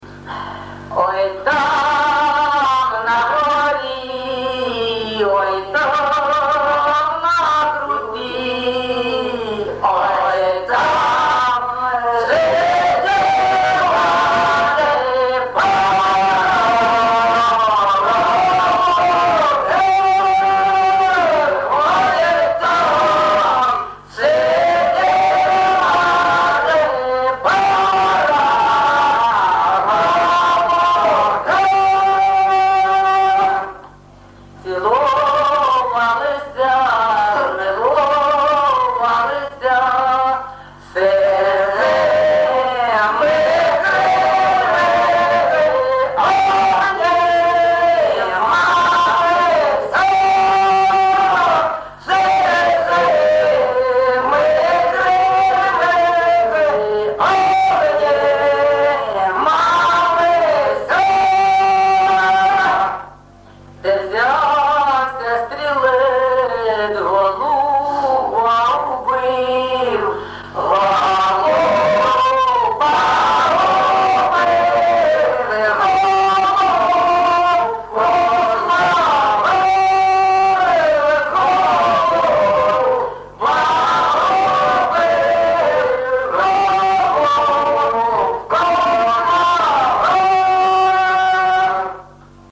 ЖанрБалади
Місце записум. Єнакієве, Горлівський район, Донецька обл., Україна, Слобожанщина